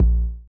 ENE Kick.wav